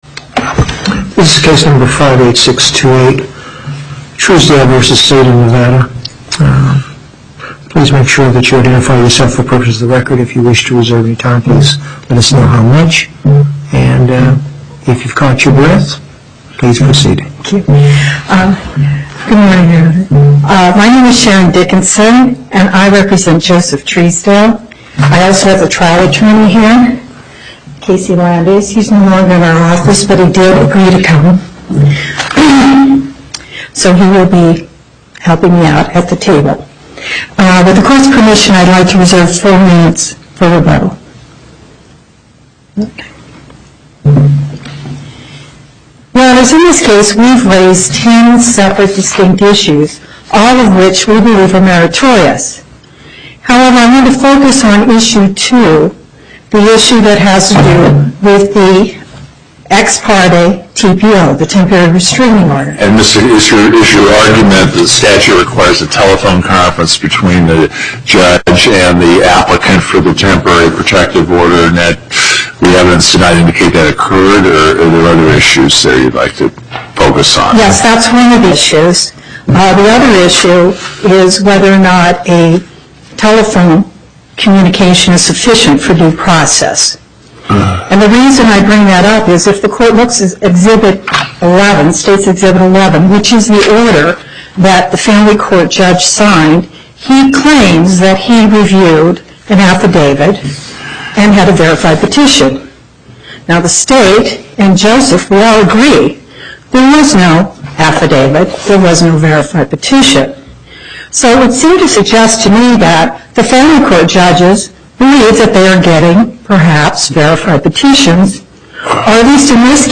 Loading the player Download Recording Docket Number(s): 58628 Date: 12/13/2012 Time: 11:30 A.M. Location: Las Vegas Before the SNP12: Douglas, Gibbons, Parraguirre.